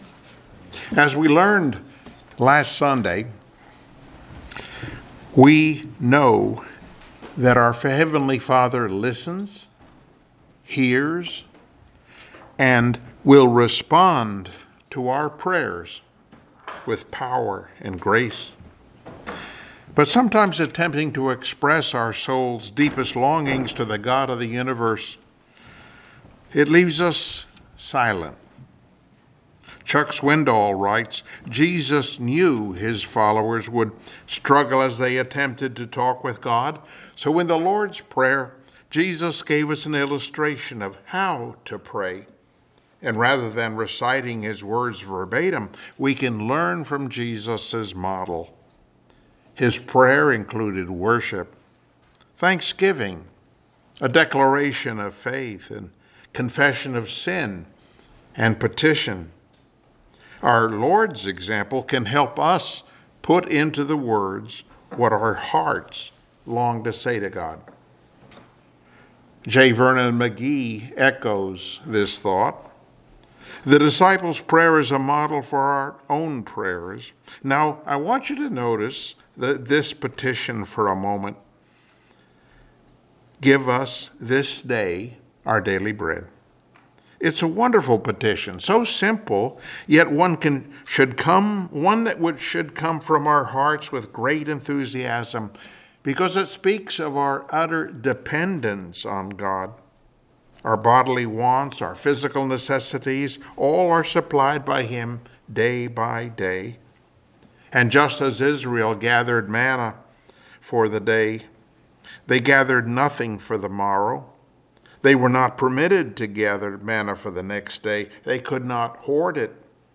Passage: Mathew 6:11 Service Type: Sunday Morning Worship